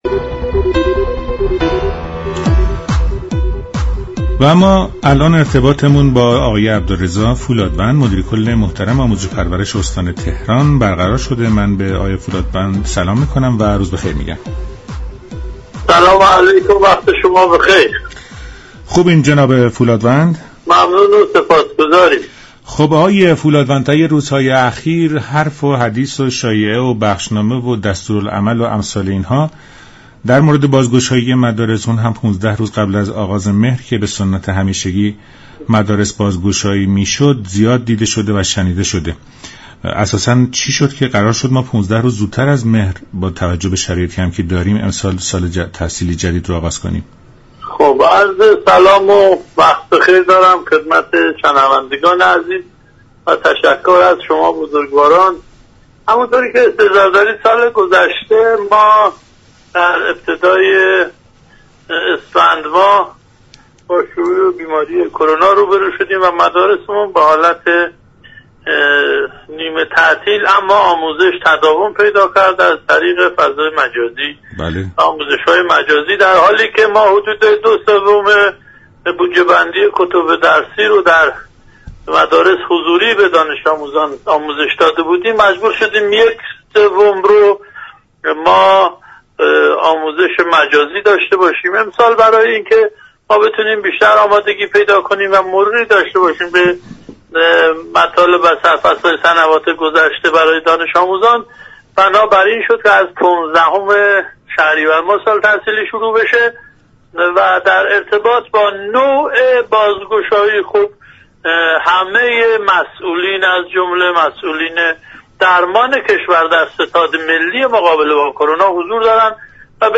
وی ادامه داد: آموزش و پرورش به تتهایی توان عملیاتی كردن شیوه نامه های بهداشتی ندارد و در این مسیر به كمك والدین نیاز مبرم دارد. در ادامه این گفت و گوی رادیویی، دكتر اسماعیلی نماینده و عضو كمیسیون آموزش و تحقیقات مجلس به روی خط آمد و ضمن مخالفت با تصمیم دولت در خصوص بازگشایی مدارس گفت: در زمانی كه موج سوم كرونا در راه است بر چه اساس دانش آموزان باید روانه مدرسه شوند وی خاطر نشان كرد: بازگشایی مدارس باید با دو ماه تاخیر صورت می گرفت.